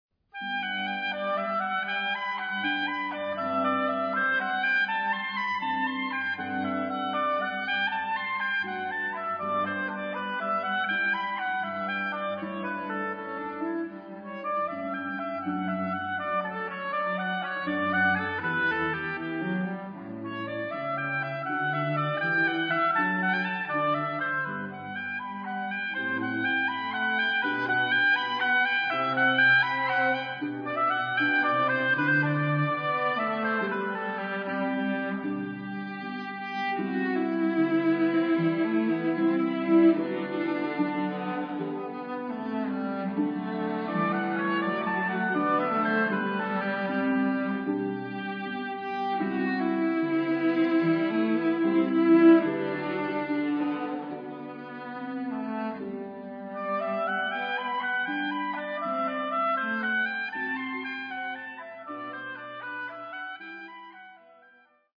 16 folk melodies, including:
over a simple string line, the drone and ornamentation giving a celtic tinge to the english melancholy.